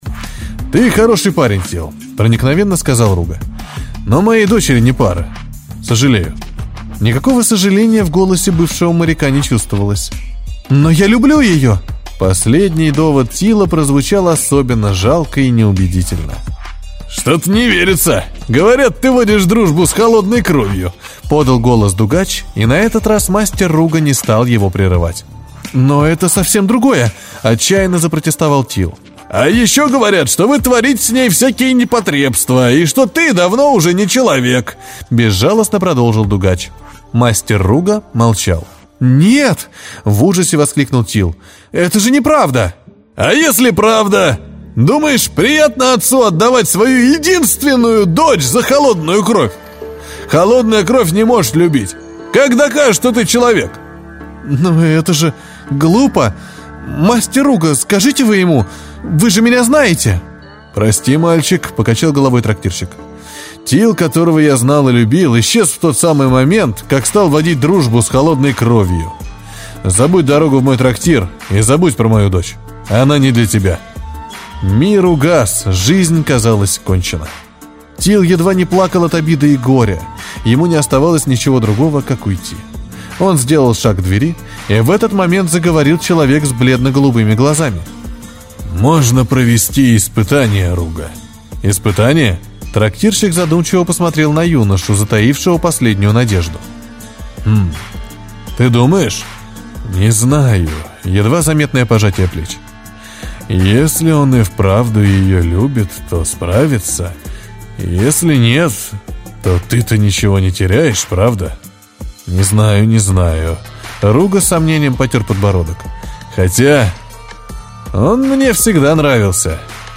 Аудиокнига Шепот моря | Библиотека аудиокниг